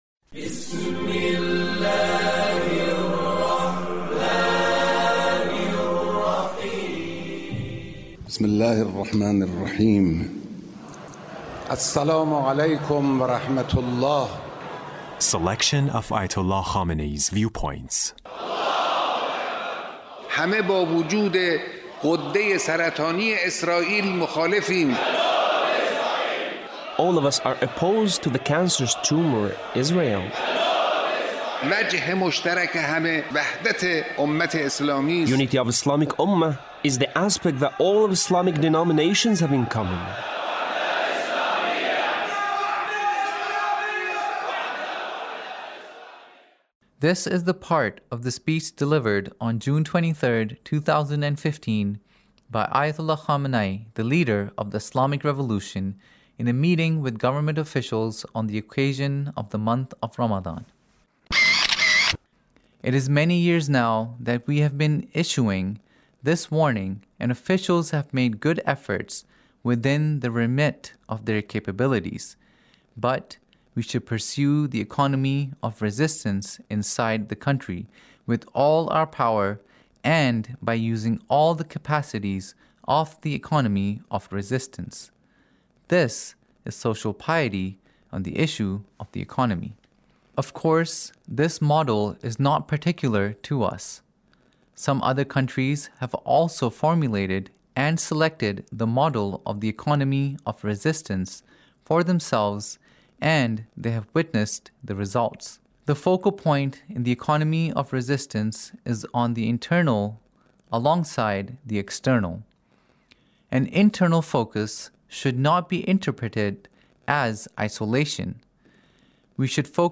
Leader's Speech On The Month of Ramadhan in a Meeting with the Government Officials